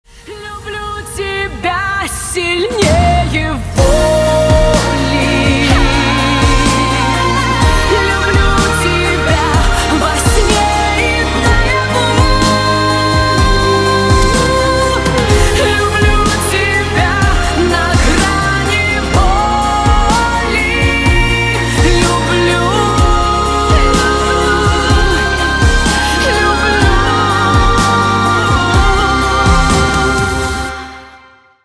• Качество: 192, Stereo
Баллада от Каролины